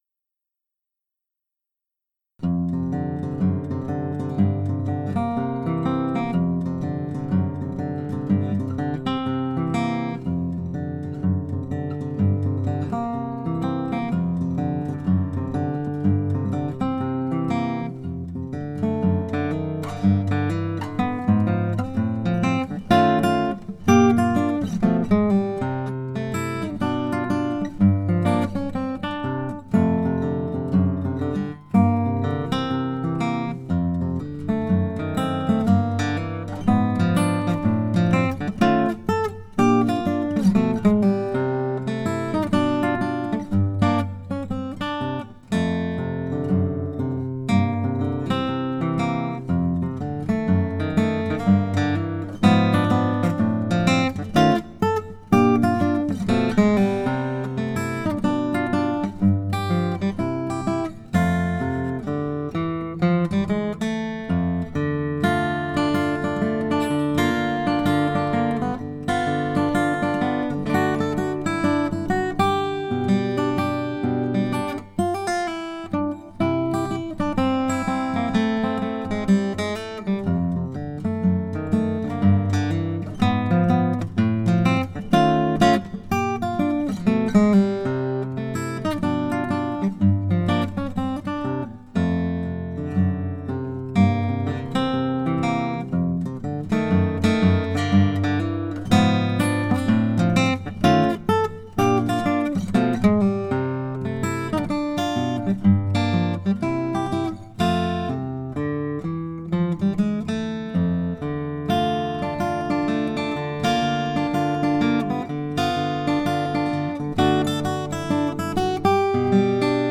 Редко делать обложки, но это весело.
Отличная игра, гладкая и простая, а гитара звучит тепло и древесно, очень хорошо!